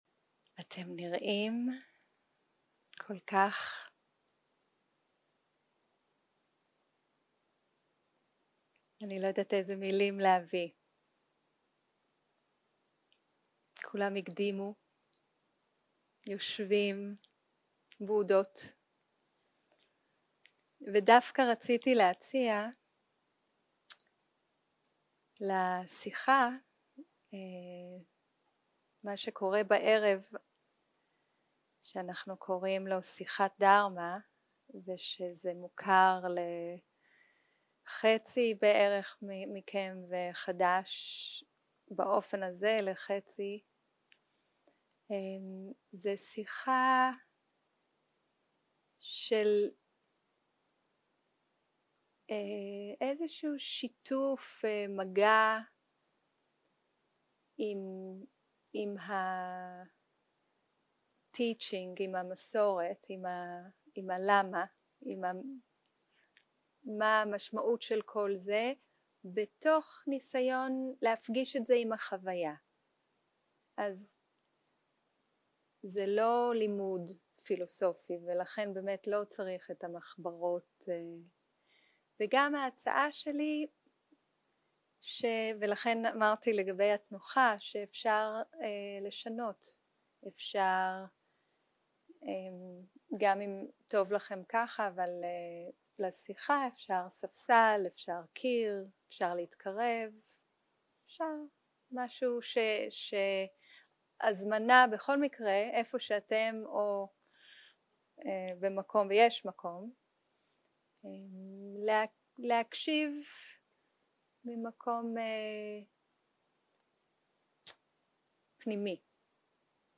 שיחות דהרמה